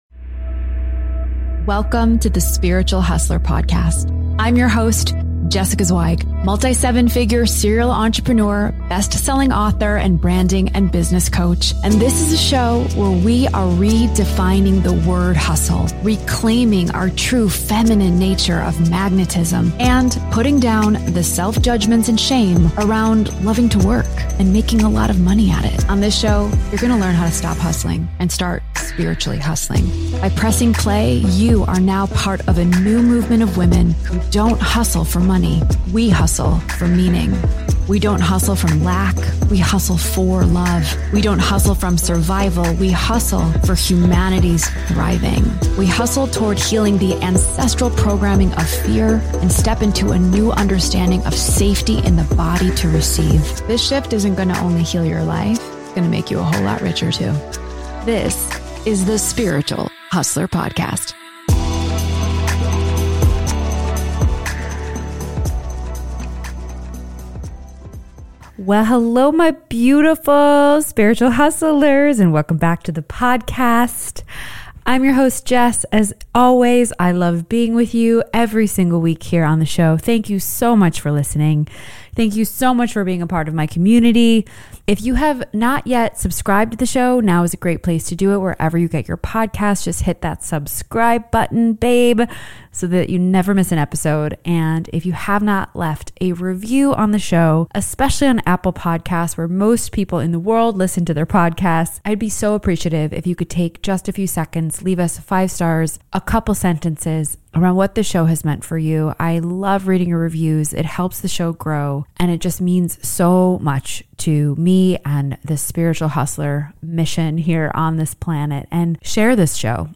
Through raw solo episodes and captivating interviews with the most inspiring female leaders of today, you will discover what it looks like to hustle from a new vibration of love, trust, meaning and service to humanity, and how it can heal your life.